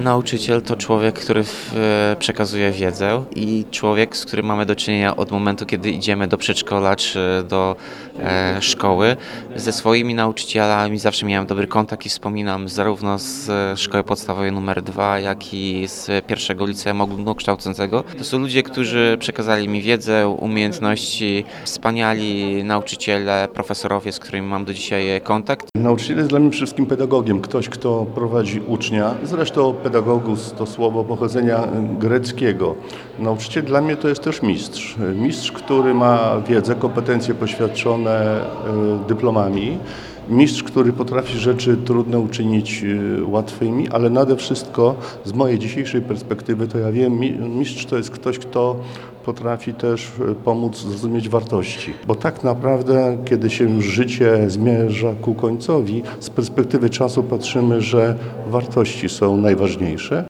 Tomasz Andrukiewicz, prezydent miasta oraz Krzysztof Marek Nowacki, Warmińsko–Mazurski Kurator Oświaty tłumaczą, kim dla nich jest nauczyciel.